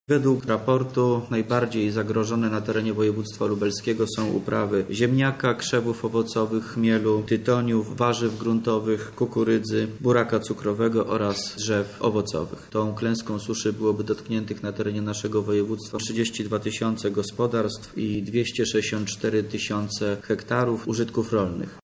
– Sytuacja na lubelszczyźnie jest naprawdę trudna – mówi Wojciech Wilk, wojewoda lubelski.